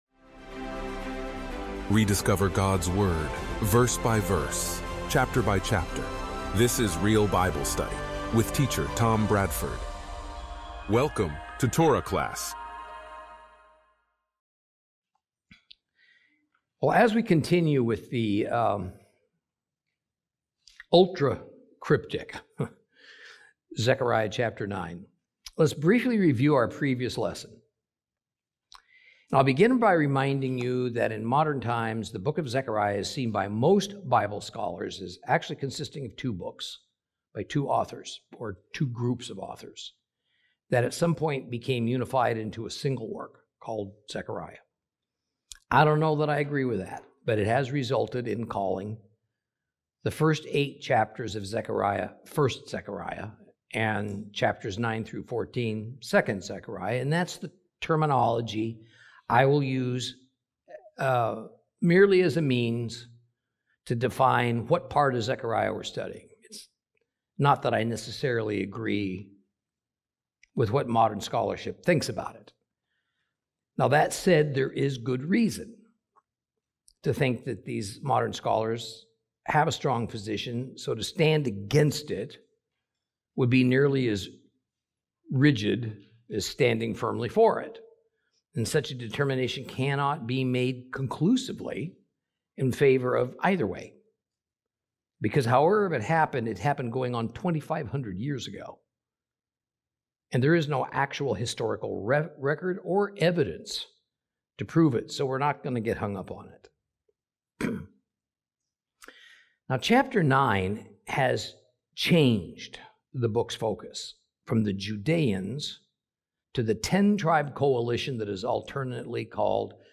Teaching from the book of Zechariah, Lesson 18 Chapter 9 continued.